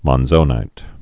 (mŏn-zōnīt, mŏnzə-nīt)